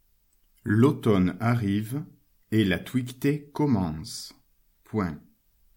Vous retrouvez ici les phrases à écrire, lues par le maître.